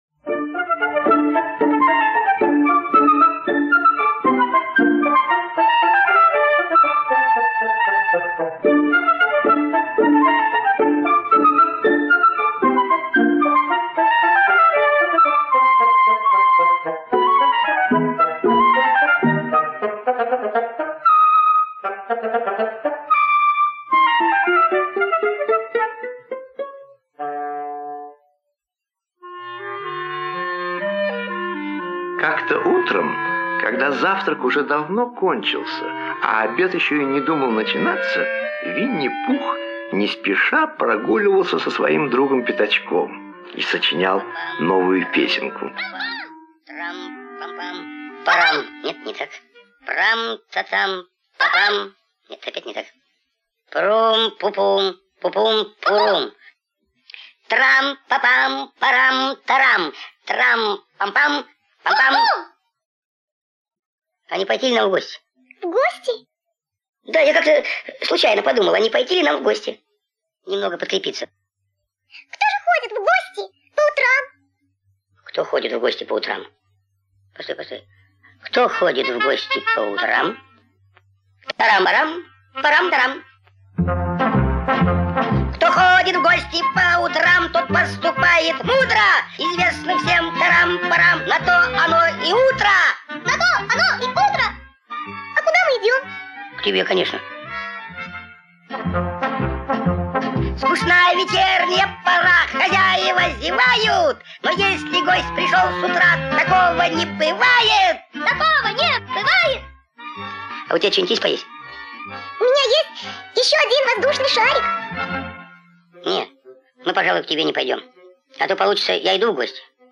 Винни-Пух идет в гости - аудиосказка Милна - слушать онлайн